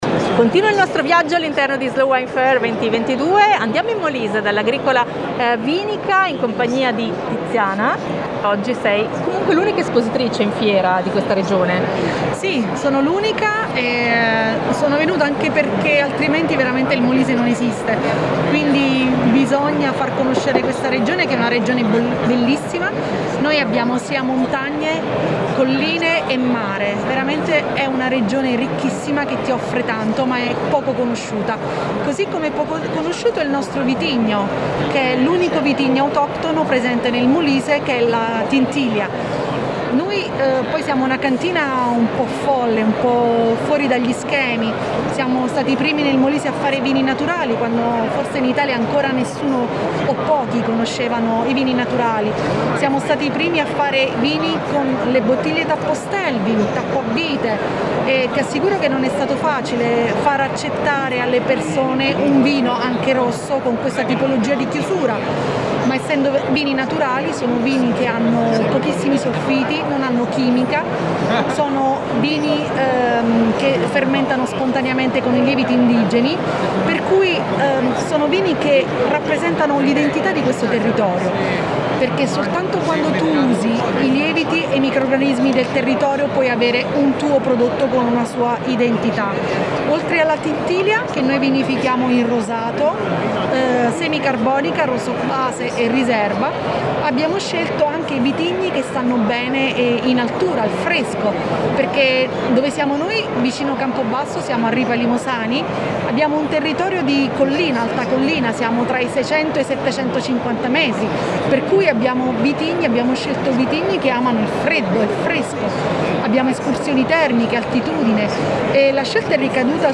Slow Wine Fair – Fiera di Bologna – 27-28-29 Marzo